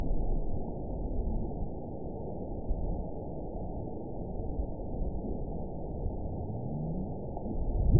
event 918042 date 04/27/23 time 19:37:52 GMT (2 years ago) score 9.14 location TSS-AB05 detected by nrw target species NRW annotations +NRW Spectrogram: Frequency (kHz) vs. Time (s) audio not available .wav